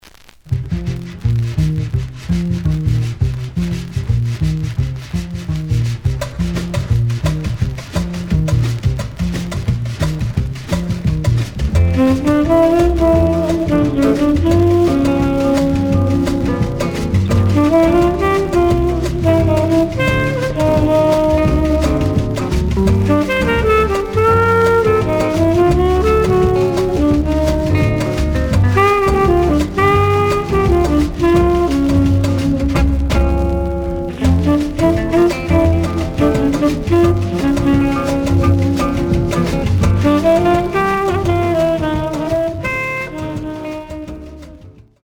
The audio sample is recorded from the actual item.
●Genre: Modern Jazz, Cool Jazz